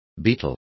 Complete with pronunciation of the translation of beetle.